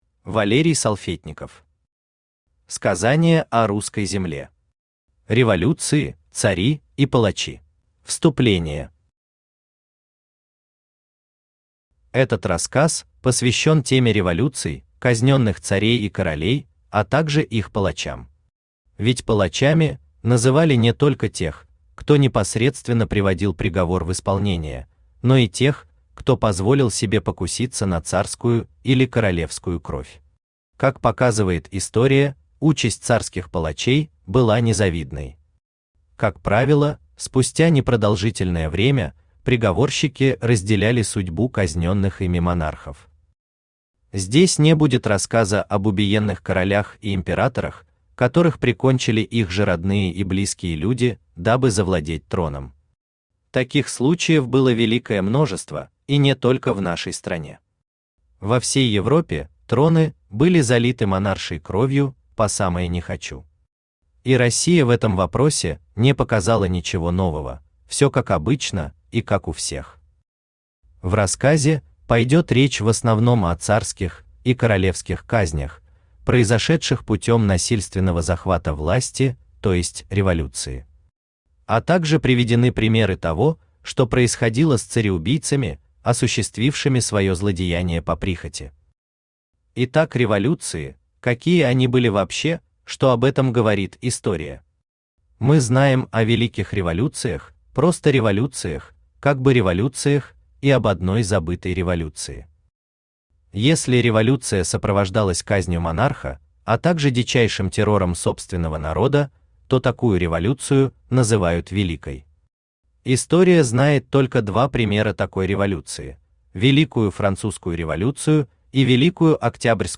Аудиокнига Сказание о Русской земле. Революции, цари и палачи | Библиотека аудиокниг
Революции, цари и палачи Автор Валерий Салфетников Читает аудиокнигу Авточтец ЛитРес.